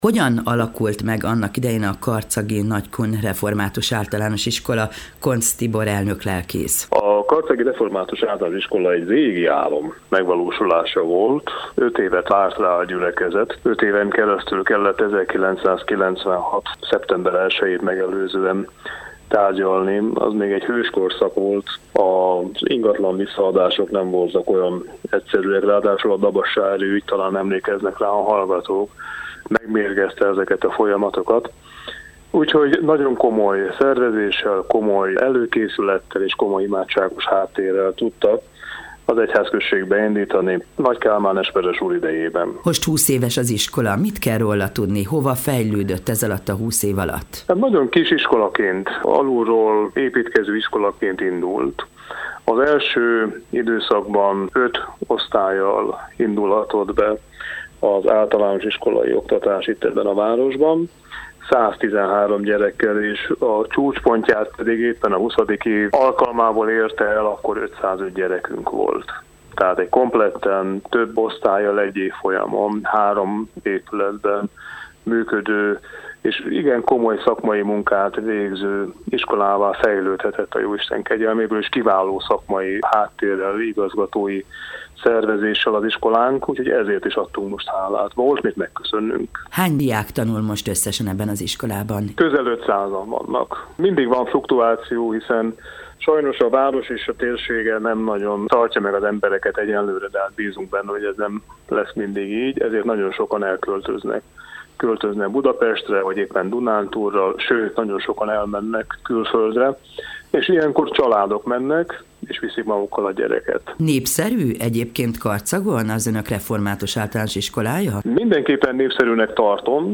Az igét dr. Fekete Károly, a Tiszántúli Református Egyházkerület püspöke hirdette a 8. zsoltár alapján.